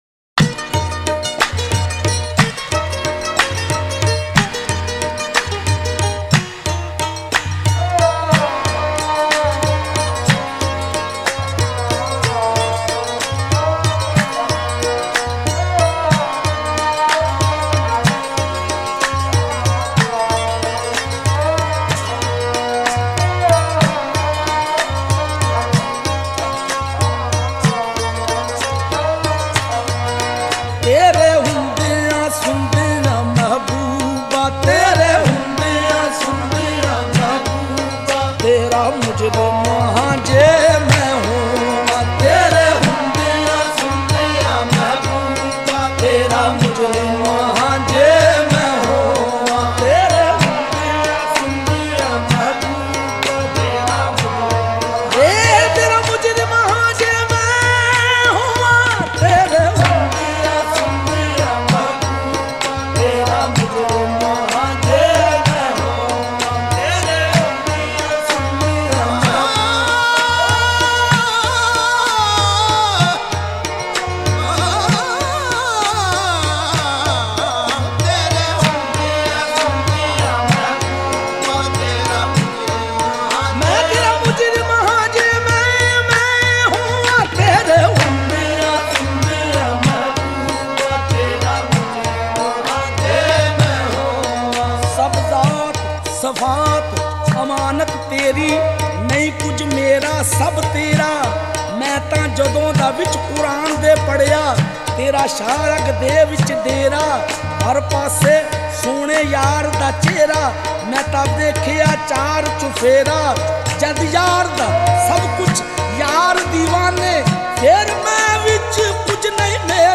Punjabi love song/Hamd with a remarkable tune
Sufi Songs